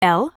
OCEFIAudio_en_LetterL.wav